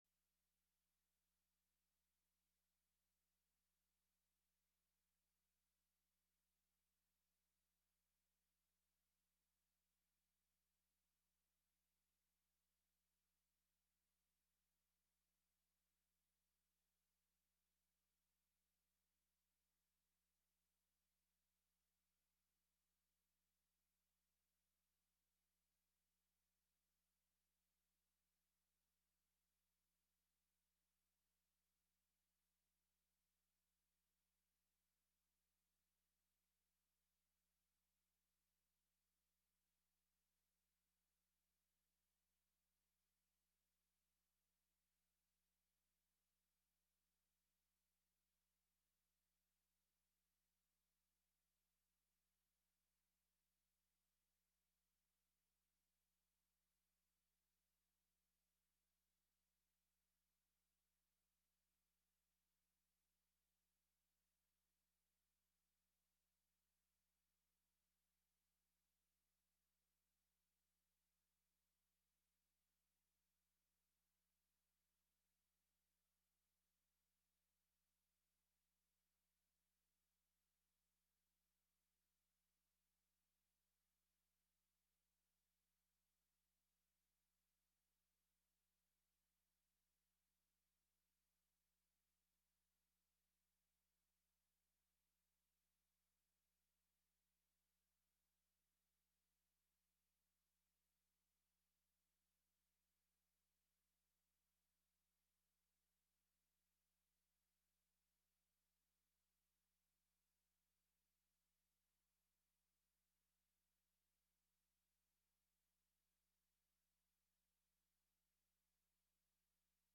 Live from Shift with Screen Compositions